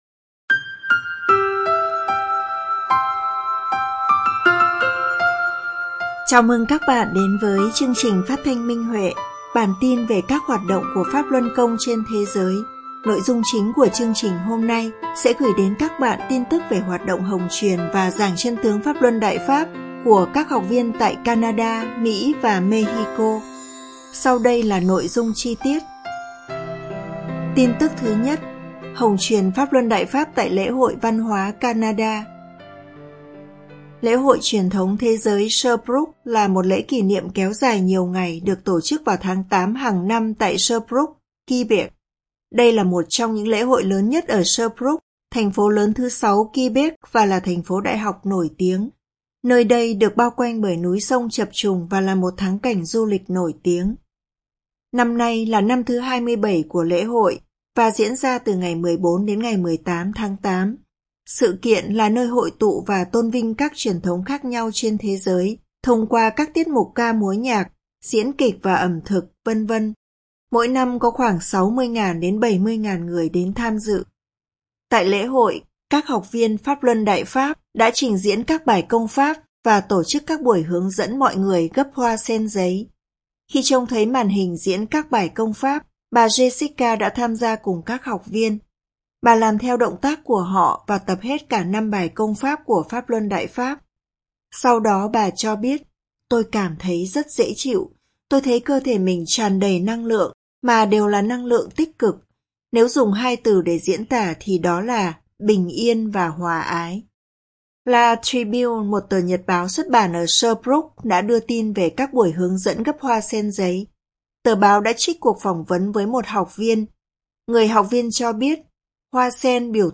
Chương trình phát thanh số 211: Tin tức Pháp Luân Đại Pháp trên thế giới – Ngày 26/8/2024